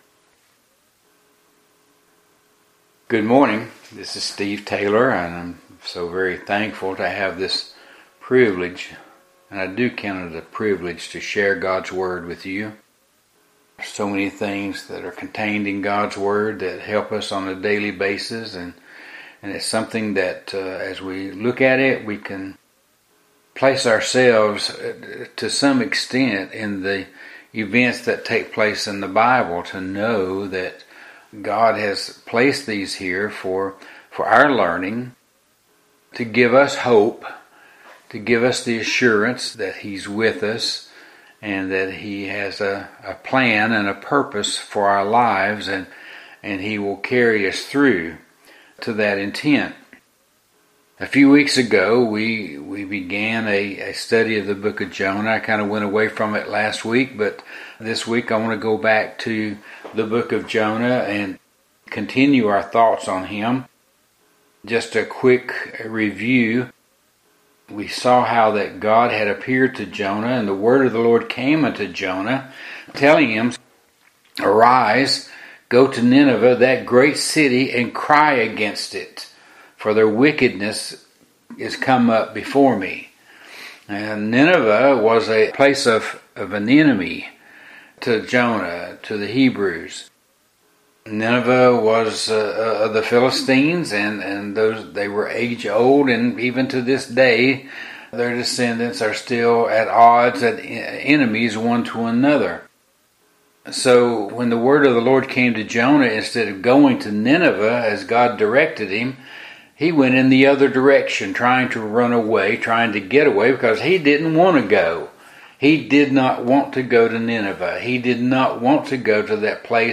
Today's Sermon